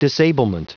Prononciation du mot disablement en anglais (fichier audio)
Prononciation du mot : disablement